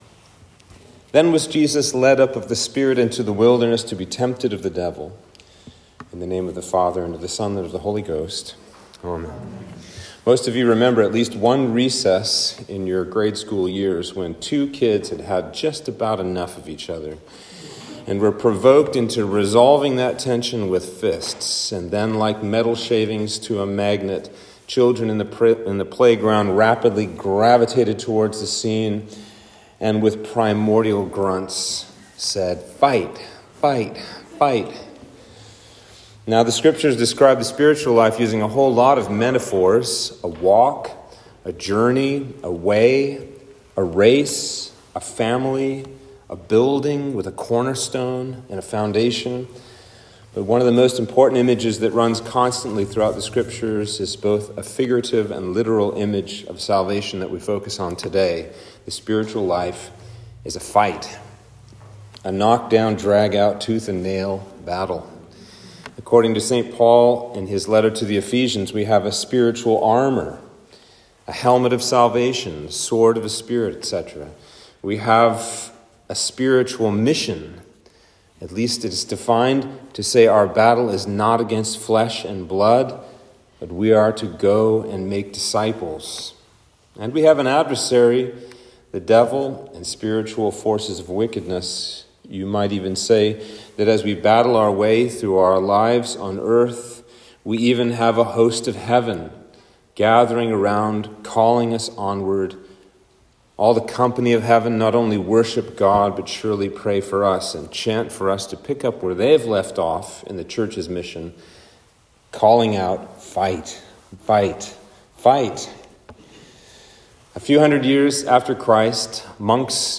Sermon for Lent 1